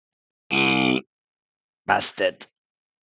ad busted eenk Meme Sound Effect